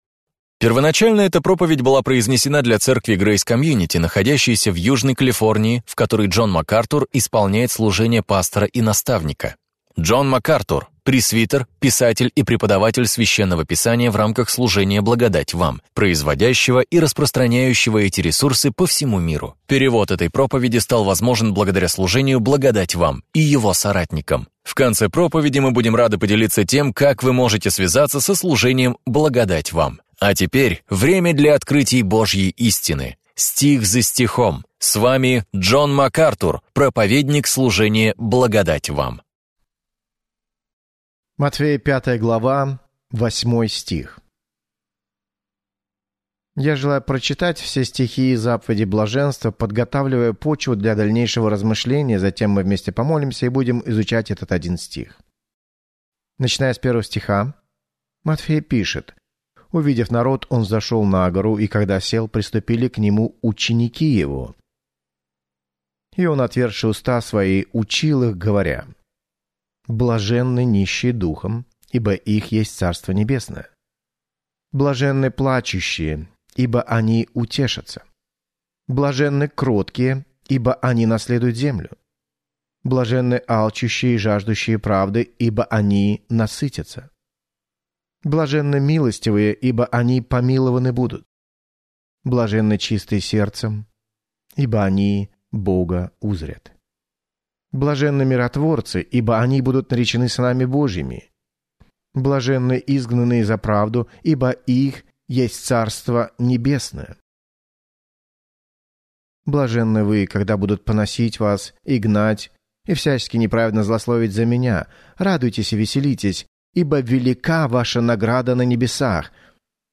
Можем ли мы обладать истинным непреходящим счастьем? В своей проповеди «Заповеди блаженства» Джон Макартур делает обзор утверждений Христа – заповедей блаженства, исследующих моральные, этические и духовные предписания, которыми руководствуются Божьи люди.